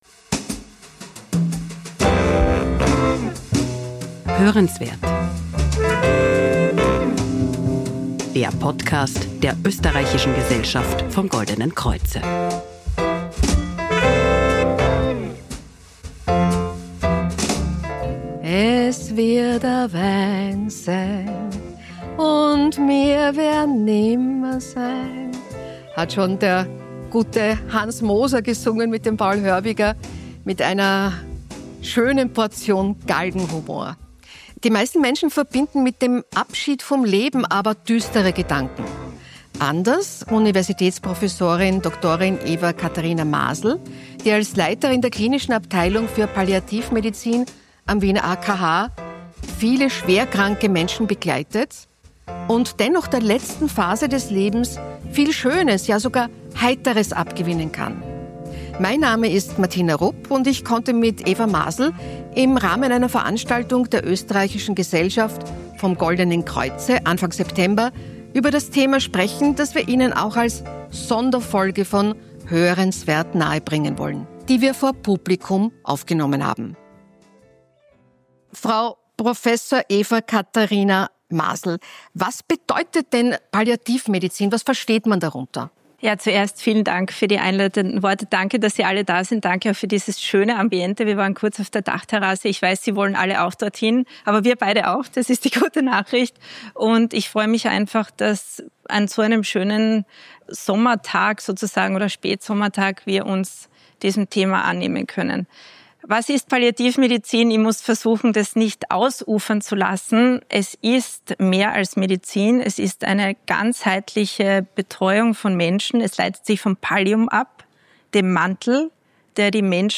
Moderatorin Martina Rupp sprach im Rahmen einer Veranstaltung mit ihr über das Thema – zu hören als 57. Episode von HÖRENSWERT.